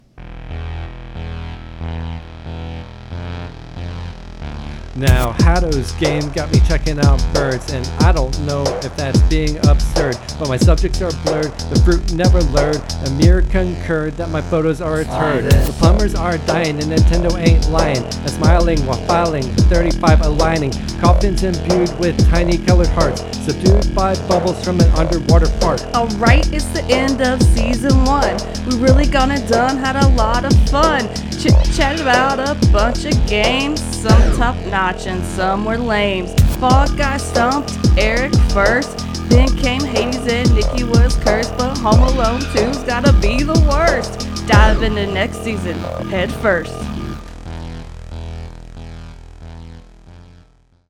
Rap from Episode 14: End of Season Rap – Press any Button
End-of-Season-1-Rap.mp3